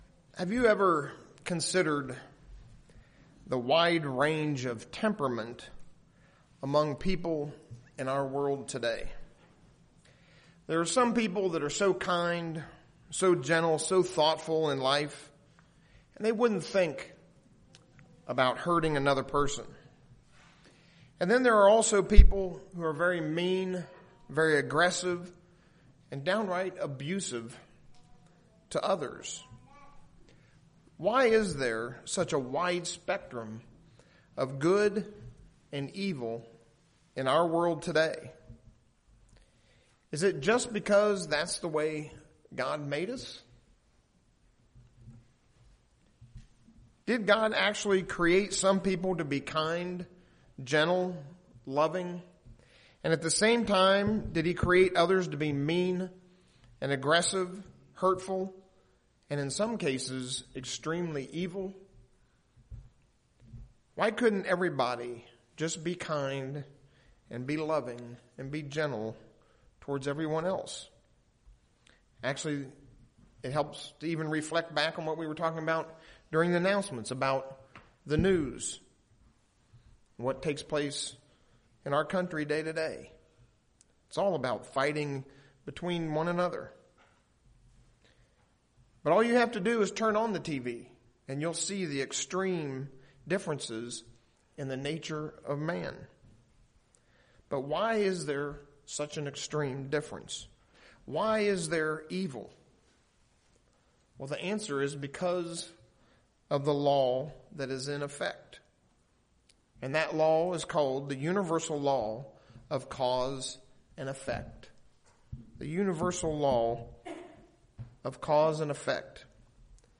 Why is there such a wide spectrum of good and evil today? This sermon explains the law of cause and effect as it pertains to Satan and this world.
Given in Ft. Wayne, IN